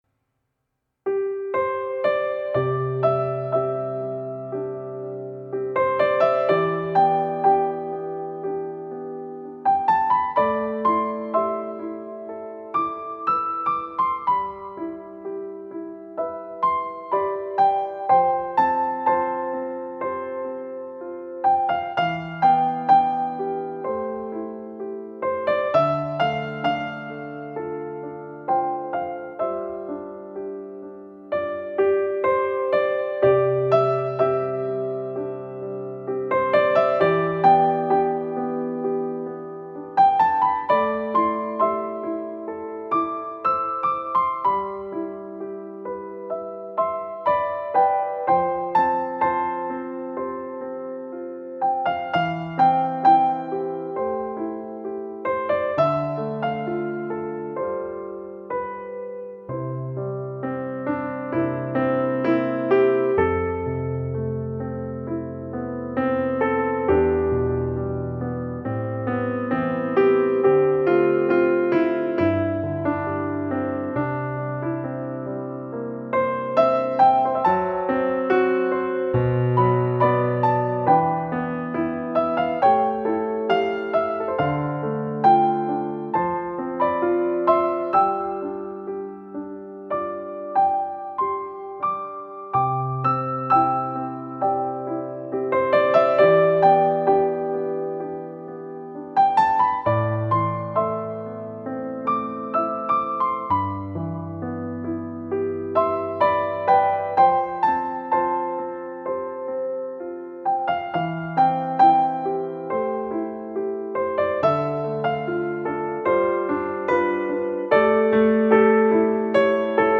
钢琴版